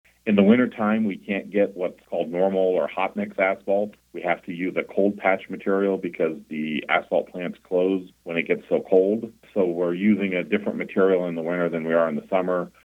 He says roughly 300,000 pounds of patch material was put onto Manhattan roadways in January.